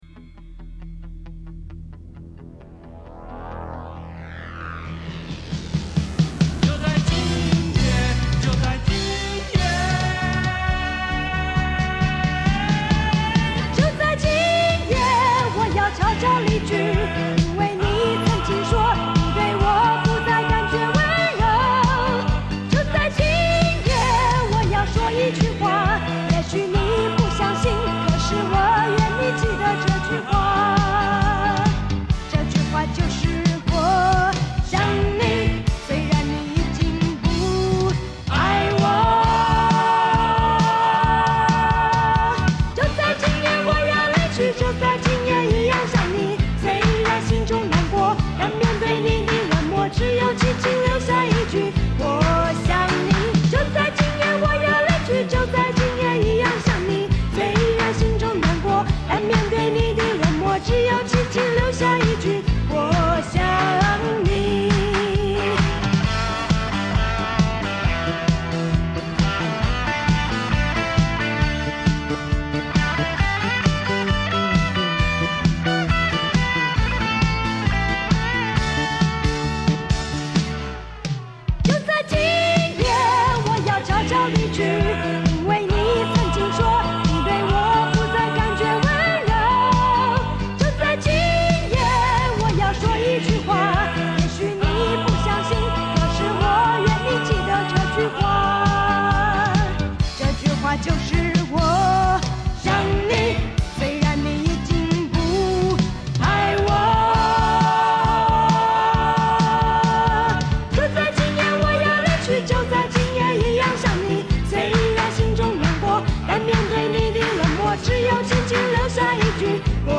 簡單白話的歌詞伴隨熱力十足的搖滾節奏，最後重覆四次的副歌橋段讓人印象深刻